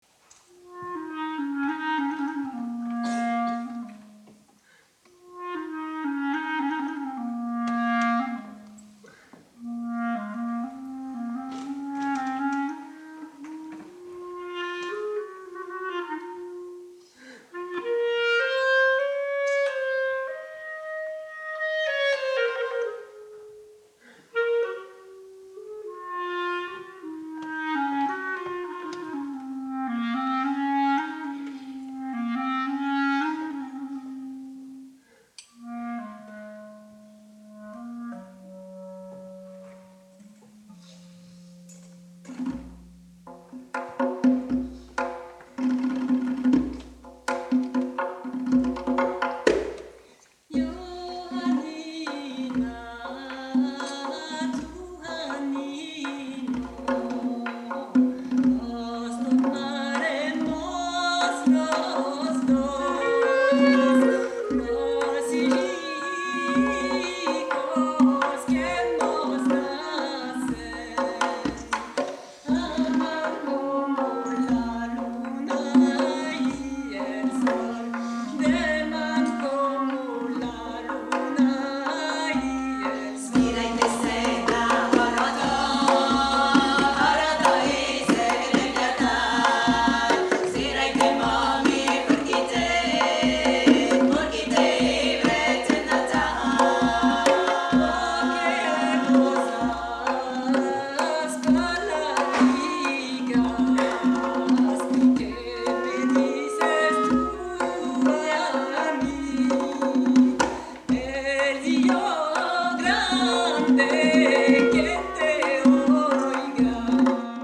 Vea videoclips de la segunda edición del concierto de gala del Instituto Europeo de Música Judía, «la música judía en todos sus estados», que tuvo lugar el domingo 6 de noviembre de 2016, a las 18h, en la Sala Cortot (París 17), frente a un publico entusiasta compuesto de casi 400 espectadores.
Esta interacción musical entre las culturas sefardí y búlgara nos ha ofrecido asi una visión por lo menos renovada del canto judeo-español.
Parte 2: Melodías sefardíes y polifonías búlgaras